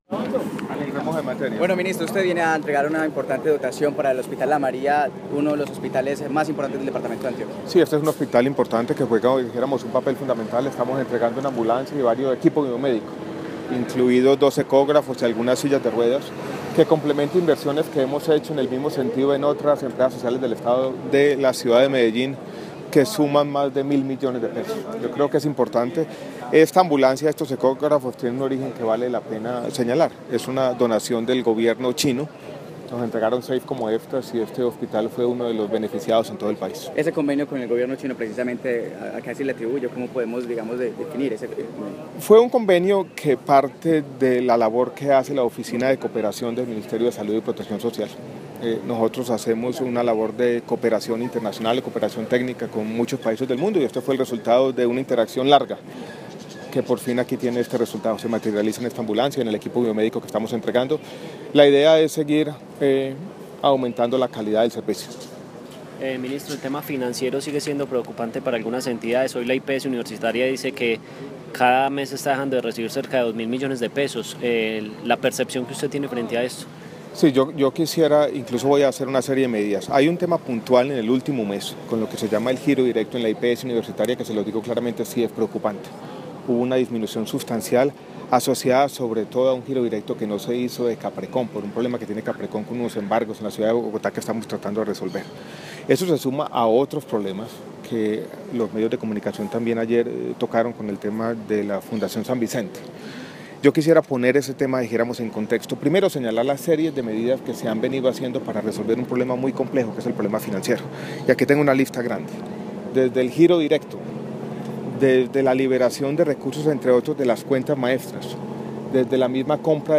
Audio: Ministro Alejandro Gaviria habla sobre entrega de ambulancia para el hospital La María y situación financiera de hospitales en Medellín